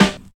70 SNARE 2.wav